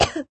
cough.ogg